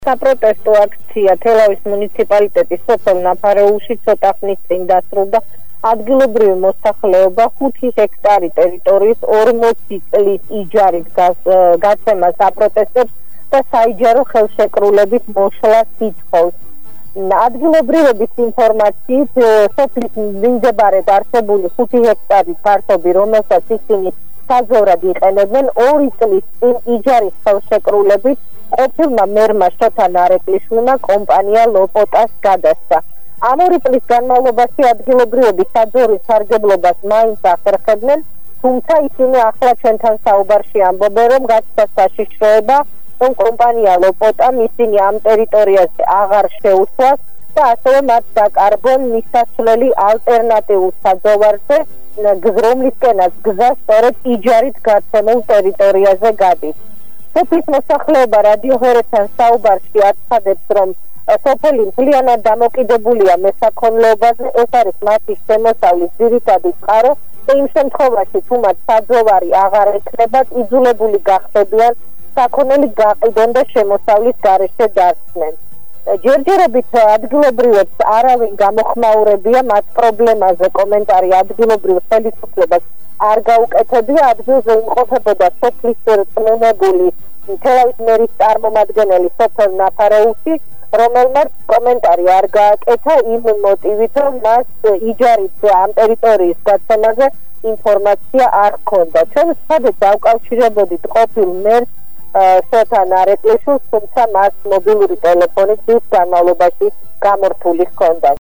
საპროტესტო აქციაზე სოფელ ნაფარეულში თელავის მერის წარმომადგენელიც იმყოფებოდა.